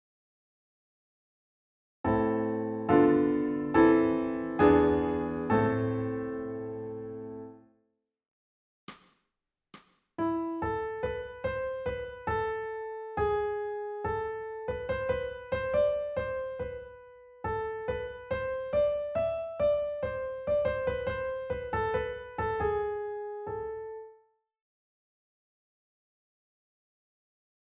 ソルフェージュ 聴音: 1-iii-19